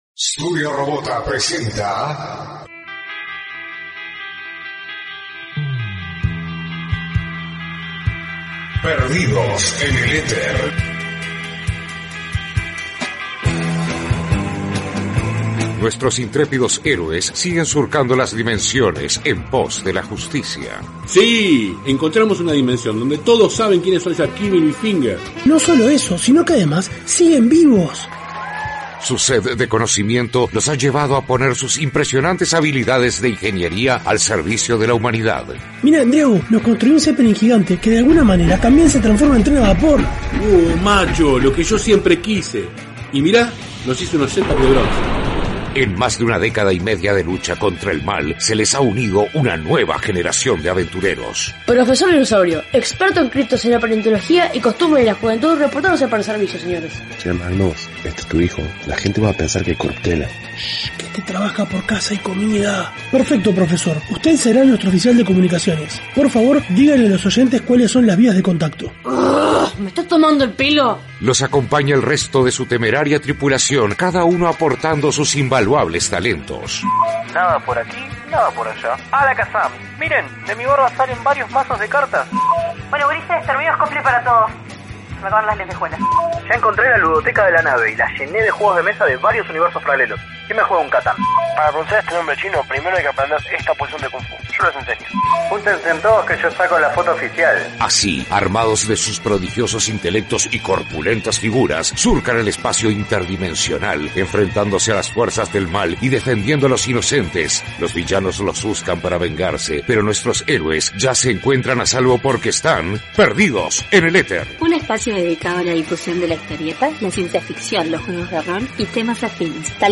Al final, segmento grabado en vivo